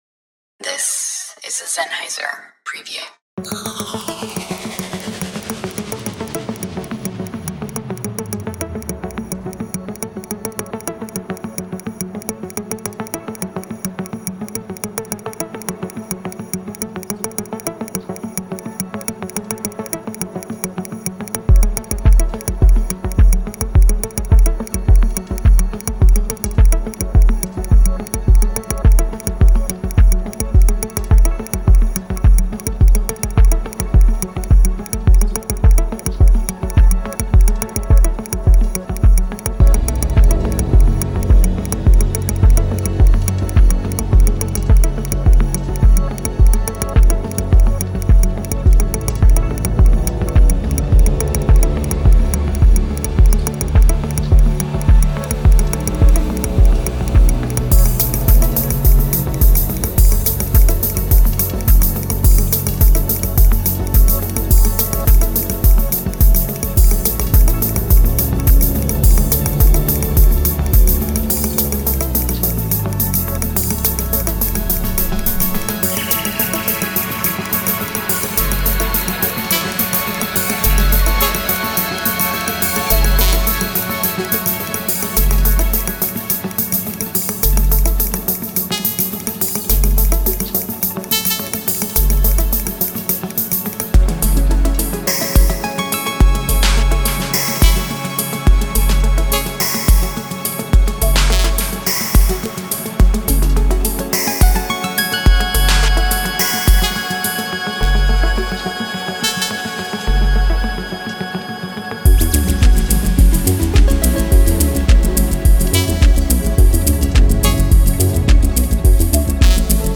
静寂が語り、機械的なものと幽玄なものが融合し、音楽が求める微妙な表現力を存分に体感できる場所です。
リズムはミニマルから催眠的なものまで幅広く、75のドラムループが完璧にシャッフルされます。
構成面では、すべてのループと音楽要素がF#マイナーで作曲されています。
デモサウンドはコチラ↓
Genre:Ambient